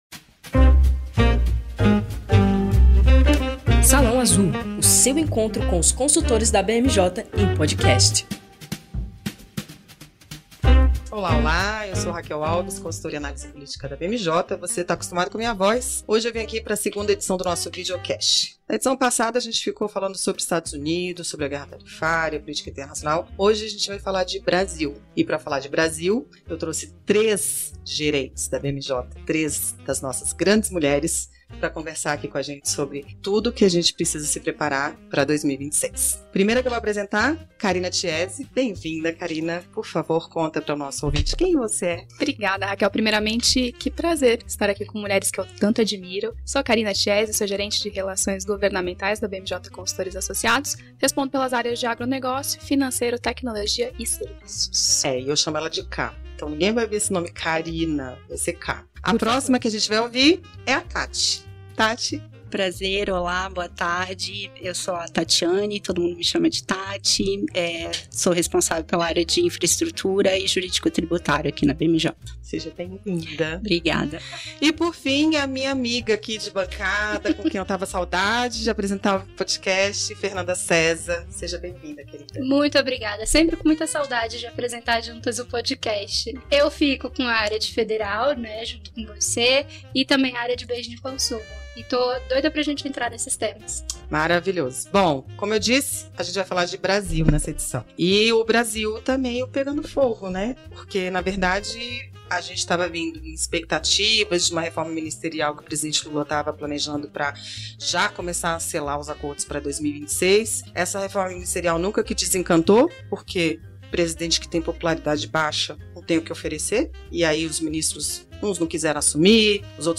A edição do nosso vídeocast já está no ar e com mulheres em peso!